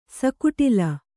♪ sakuṭila